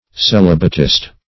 Celibatist \Ce*lib"a*tist\, n. One who lives unmarried.